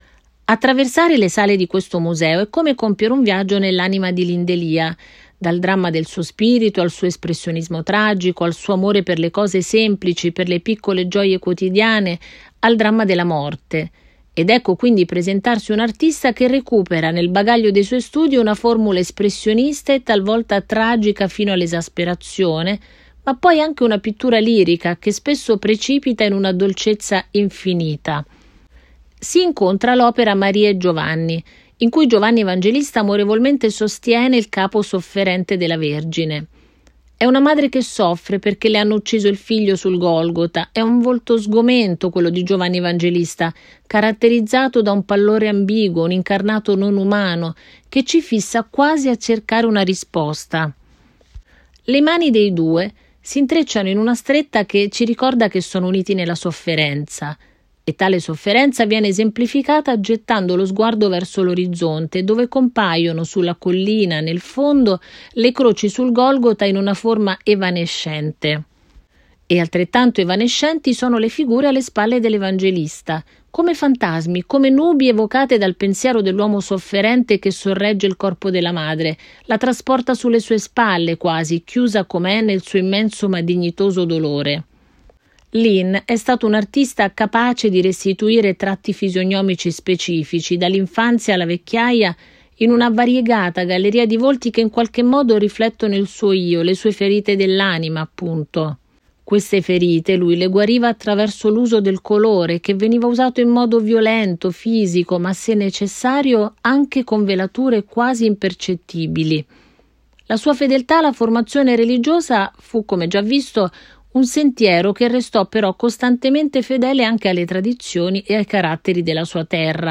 Testo Audioguide